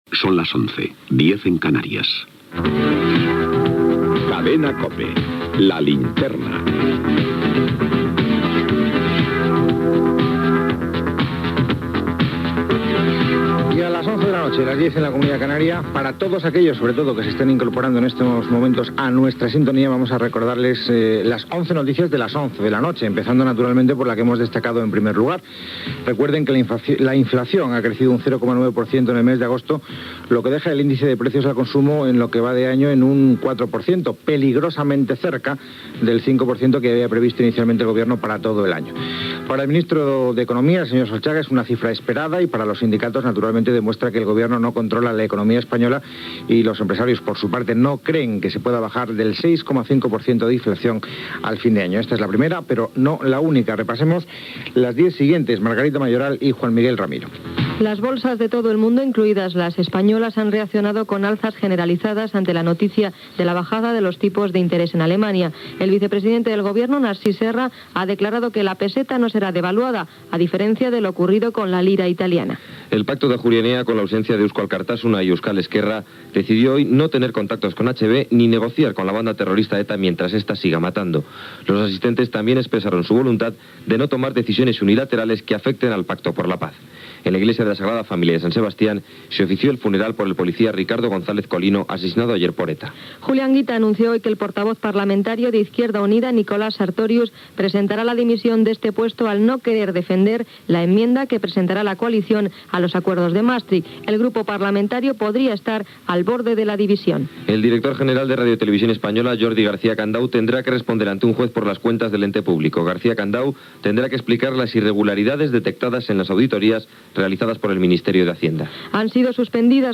Hora, indicatiu del programa, sumari informatiu
Publicitat, indicatiu, tertúlia d'actualitat del programa, Gènere radiofònic Informatiu